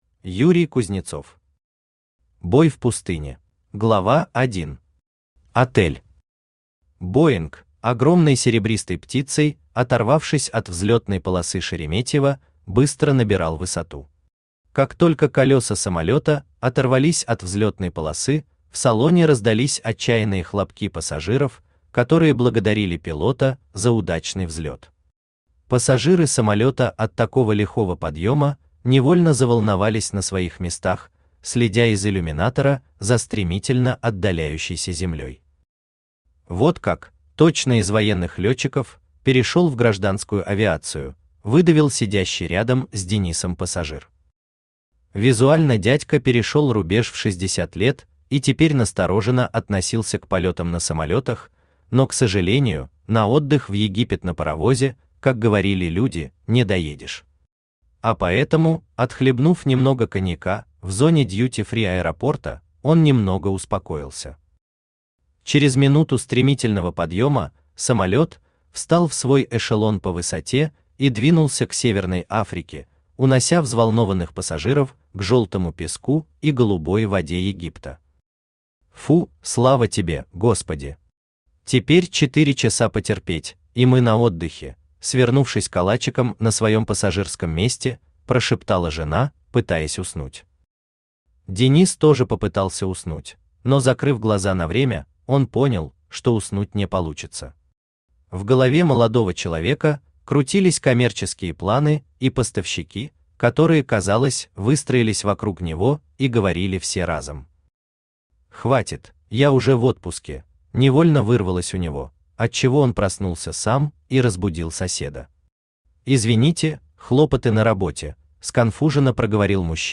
Aудиокнига Бой в пустыне Автор Юрий Юрьевич Кузнецов Читает аудиокнигу Авточтец ЛитРес.